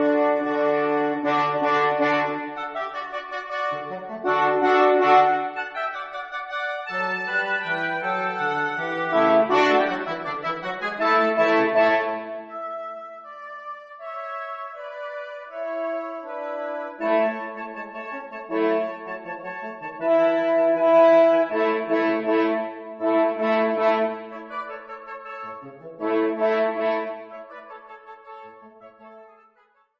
2 oboes, 2 horns, bassoon
(Audio generated by Sibelius/NotePerformer)